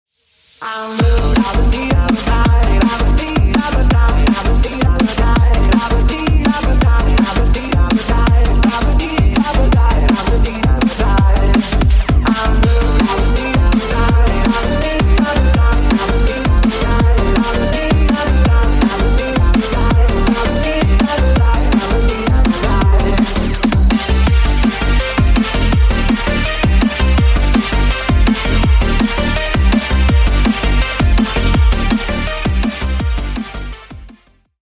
DnB Рингтоны
Рингтоны Электроника